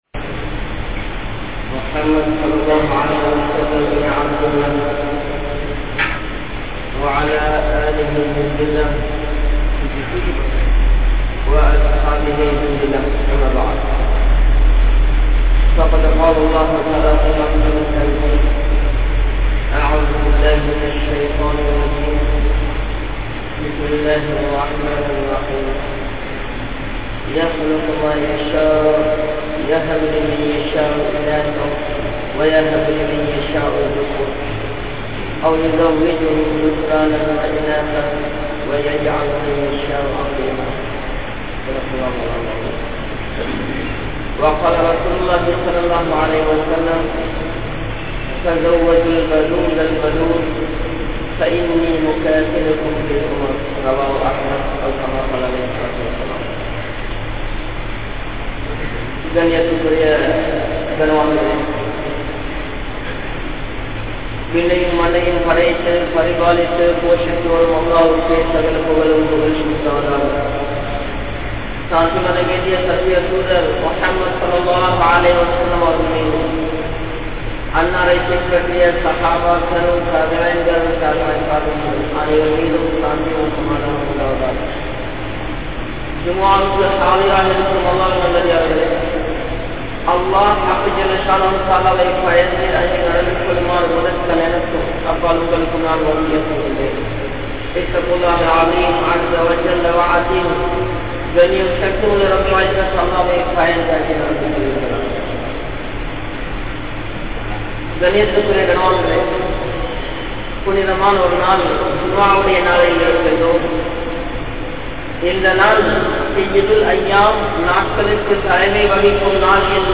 Kulanthai paakiyam | Audio Bayans | All Ceylon Muslim Youth Community | Addalaichenai